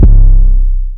808 [ new zay ].wav